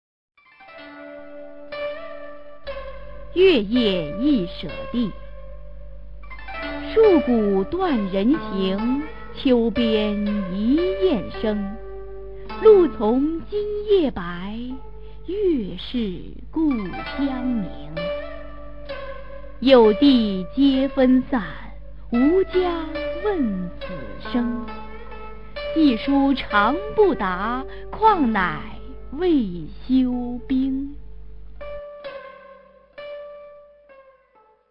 [隋唐诗词诵读]杜甫-月夜忆舍弟 古诗文诵读